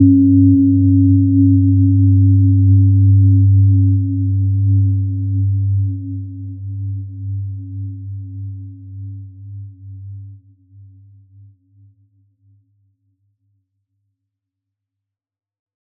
Gentle-Metallic-4-G2-mf.wav